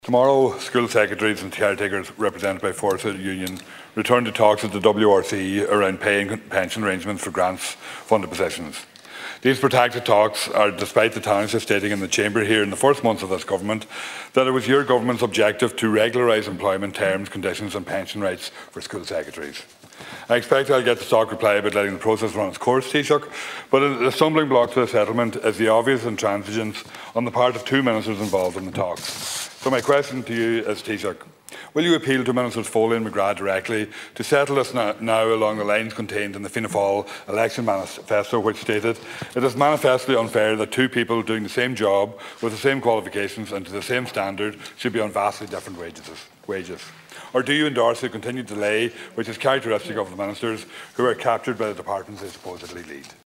Deputy Thomas Pringle in the Dail, pressed the Taoiseach to commit to resolving outstanding issues for school secretaries and caretakers.
He urged Micheal Martin to find a resolution: